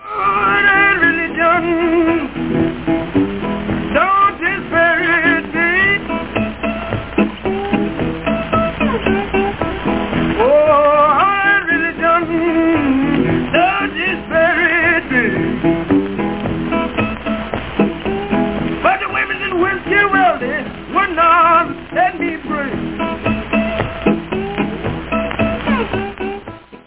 Mean Black Cat Blues - блюзовая архаика